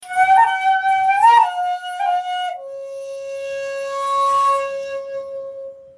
Shakuhachi 60